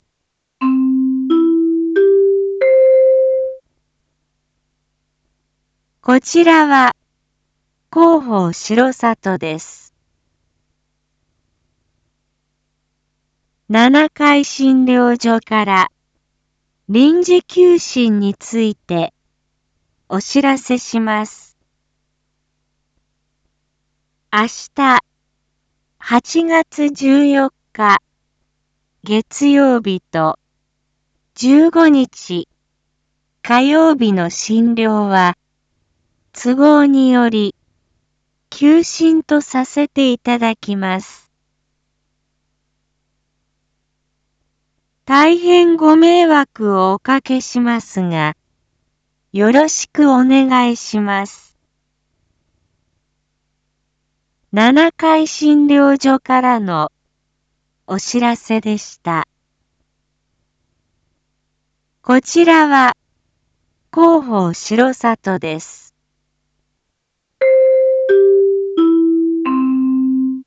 一般放送情報
Back Home 一般放送情報 音声放送 再生 一般放送情報 登録日時：2023-08-13 07:01:12 タイトル：8/13朝 七会診療所医科休診のお知らせ インフォメーション：こちらは広報しろさとです。